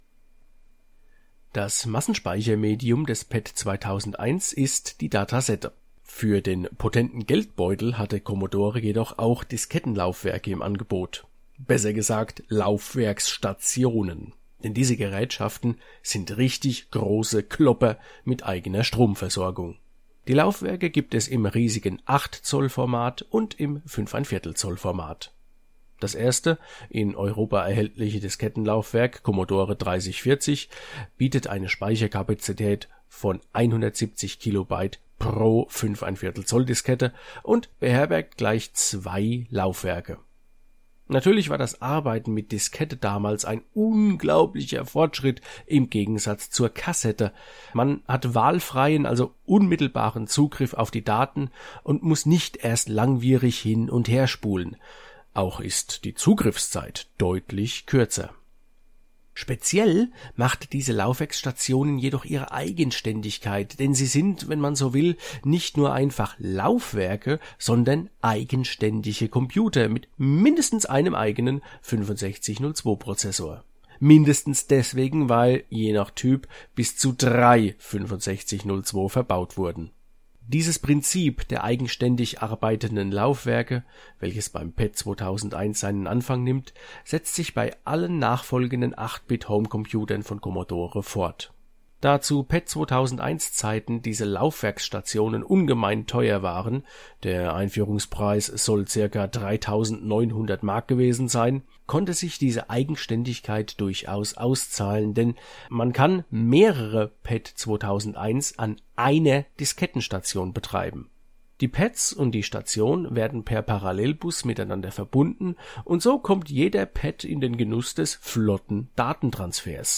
PET2001_Floppy_MBS.mp3